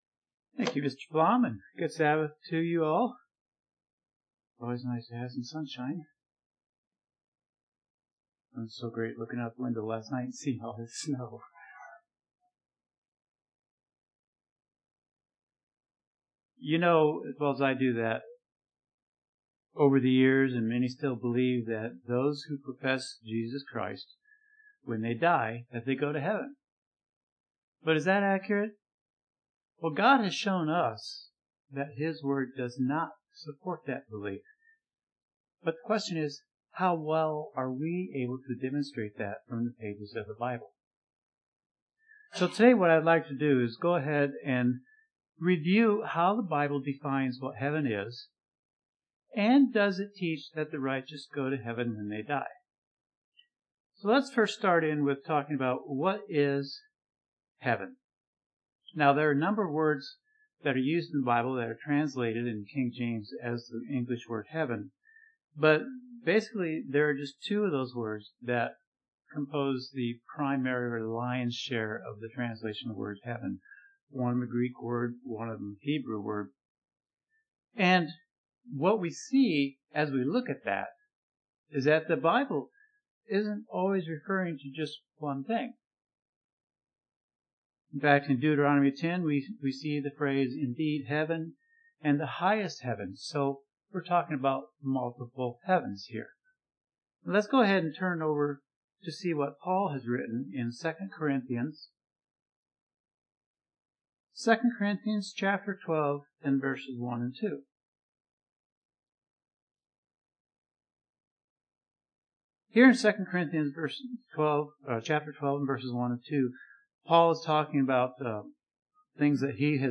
Sermons
Given in Northwest Indiana